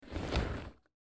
Download Bag Close sound effect for free.
Bag Close